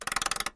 handleWind.wav